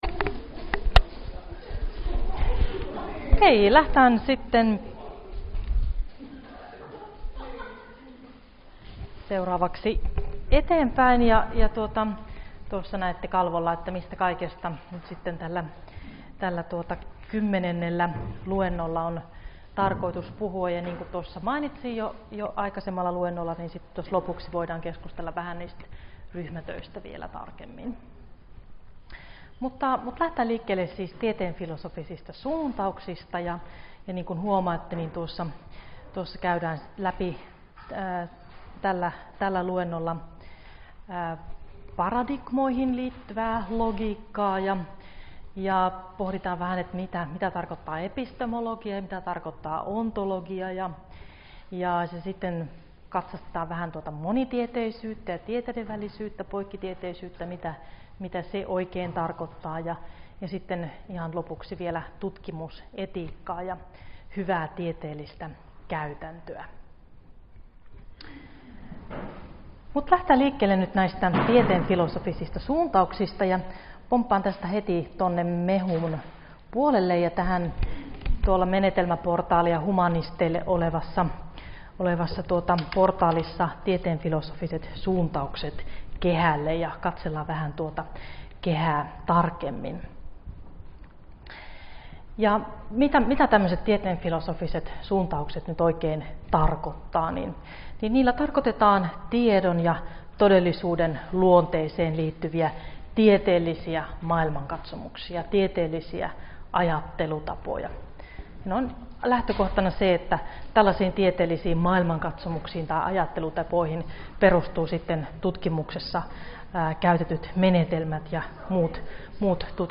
Luento 10 - Tieteenfilosofiset suuntaukset ja tutkimusetiikka — Moniviestin